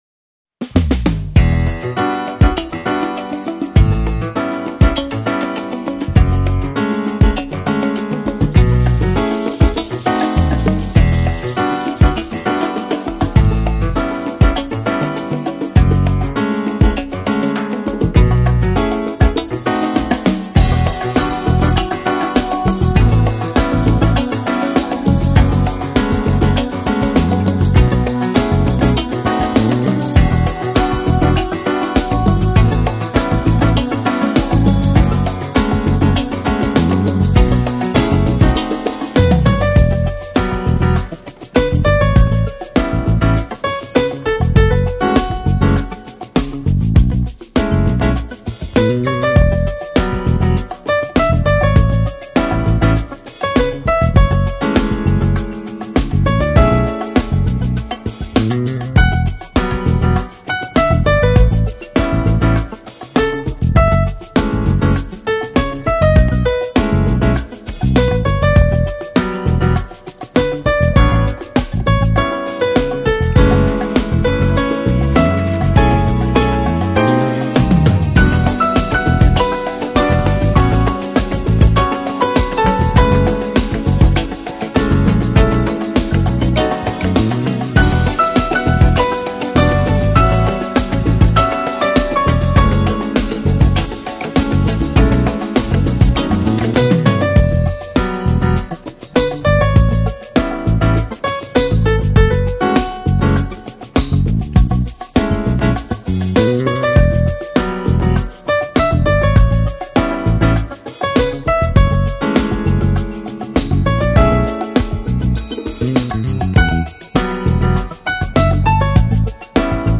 Жанр: Pop Jazz/Smooth Jazz